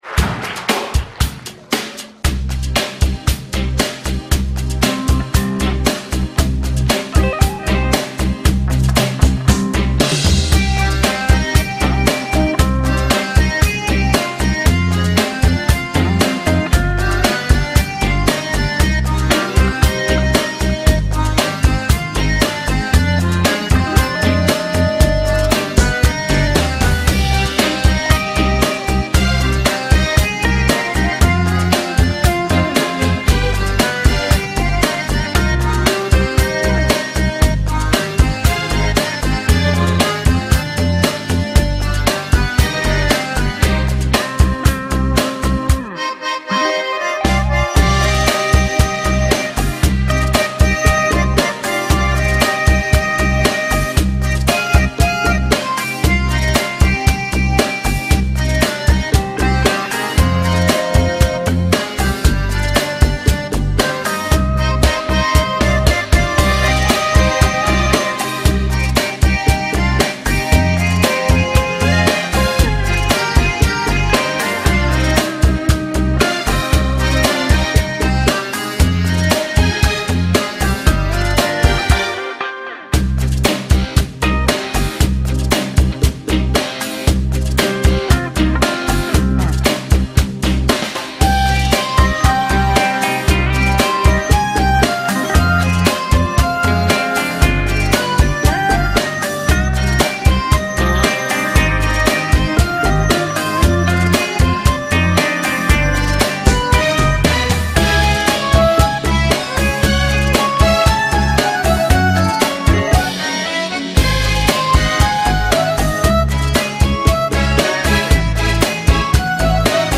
это инструментальный коллектив
Стиль: Инструментал